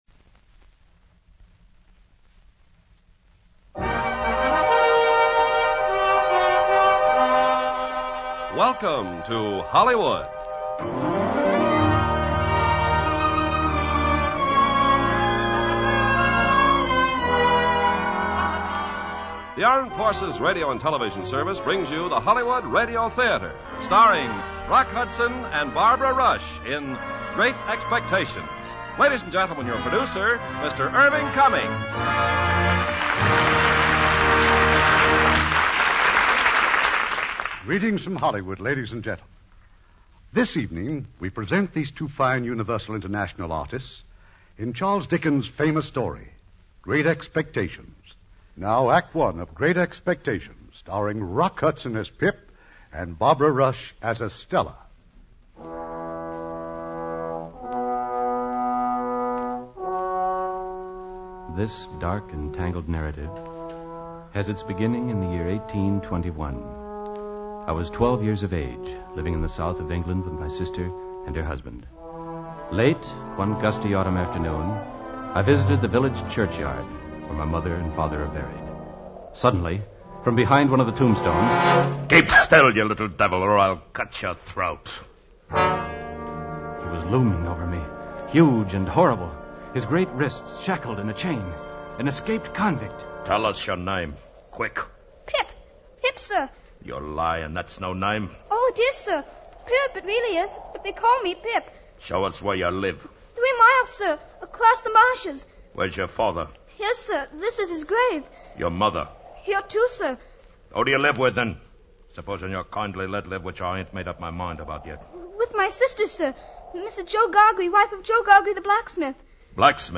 Lux Radio Theater Radio Show
Great Expectations, starring Rock Hudson, Barbara Rush